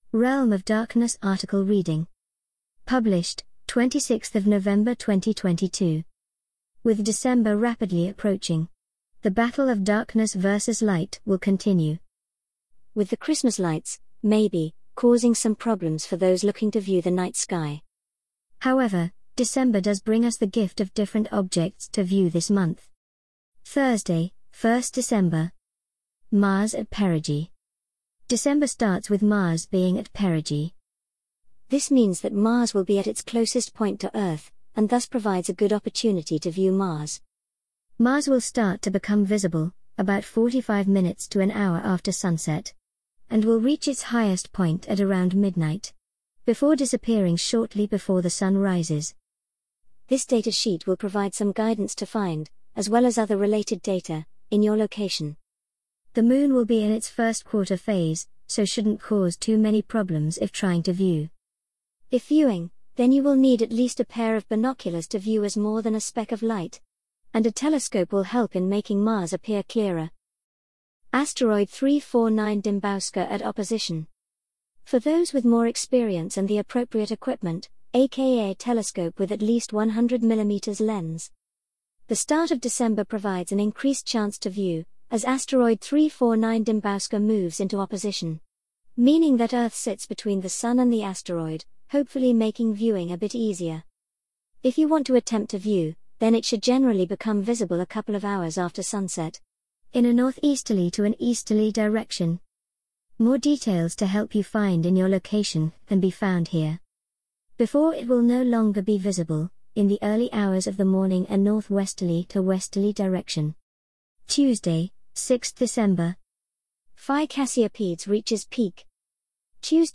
An audio reading of the Realm of Darkness December 2022 Article